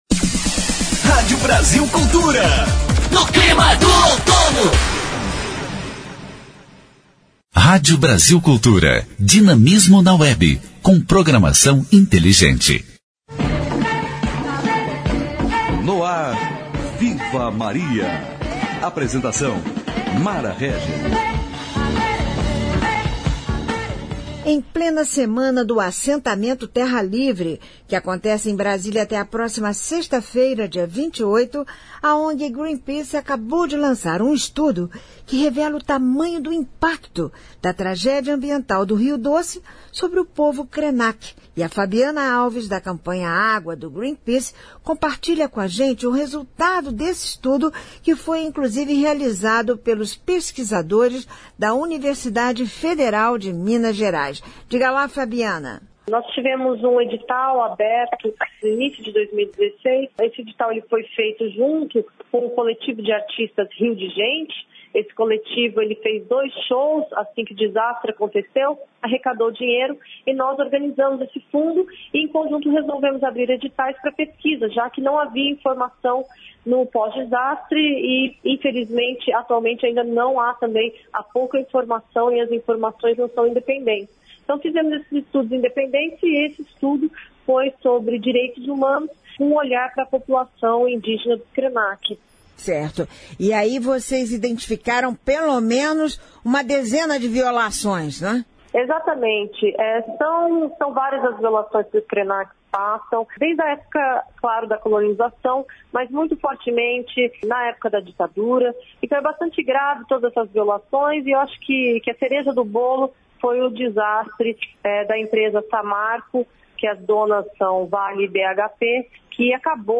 Viva Maria : Programete que aborda assuntos ligados aos direitos das mulheres e outros aspectos da questão de gênero.